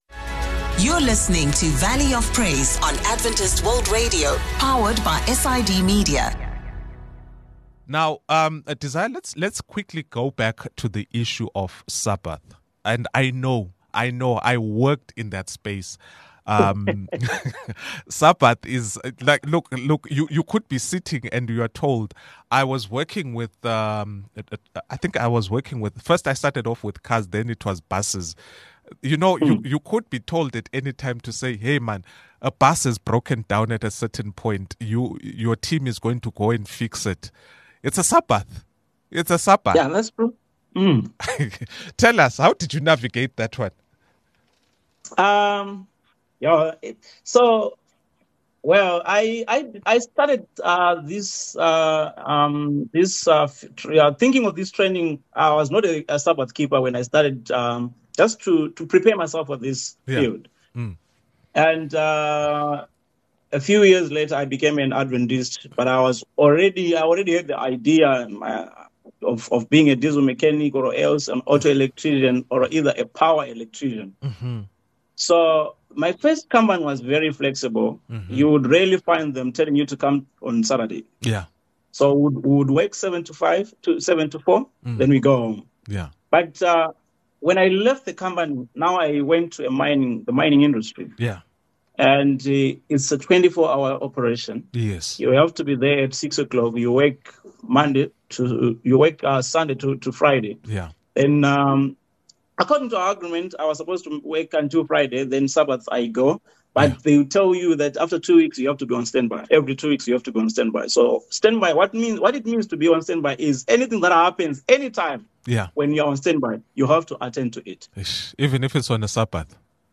In this episode of Faith Meets Profession, we sit down with a talented chef who brings faith into the kitchen. From recipes to principles, discover how they balance passion and purpose, cooking not just for the body, but also for the soul. Tune in for a conversation about integrating faith and profession in the world of culinary arts